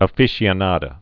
(ə-fĭshē-ə-nädə, -fĭsē-, -fēsē-)